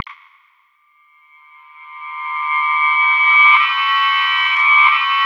BLIP      -L.wav